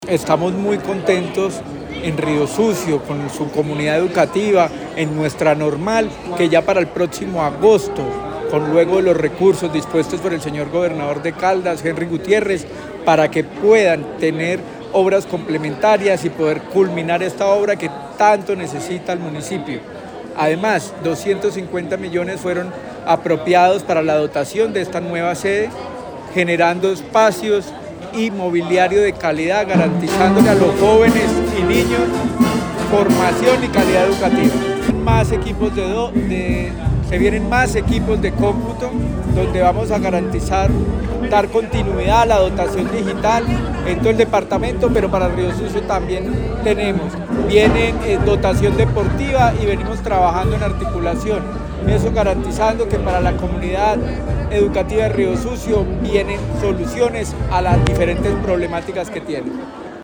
Luis Herney Vargas Barrera, secretario de Educación de Caldas
Secretario-de-Educacion-Lus-Herney-Vargas-entrega-recursos-Riosucio.mp3